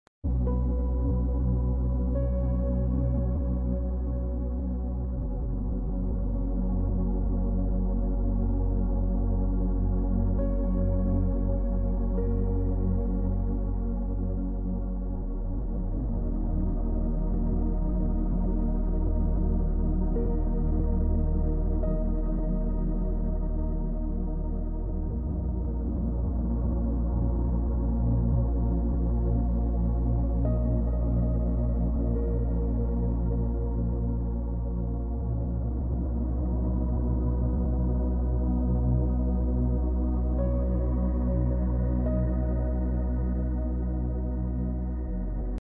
1111Hz + 888Hz. Sacred Frequency.